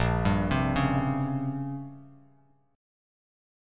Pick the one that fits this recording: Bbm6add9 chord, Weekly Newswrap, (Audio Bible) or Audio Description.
Bbm6add9 chord